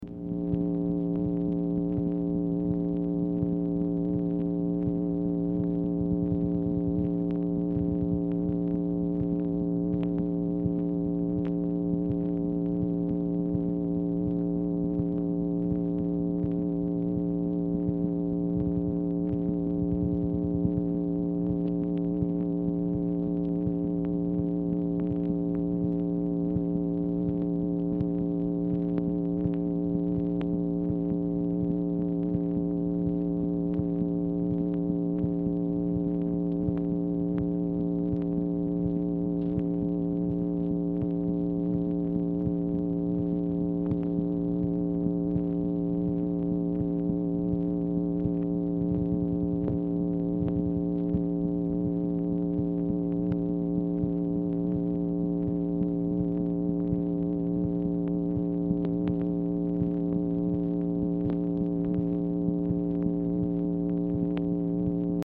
MACHINE NOISE
Format Dictation belt
Specific Item Type Telephone conversation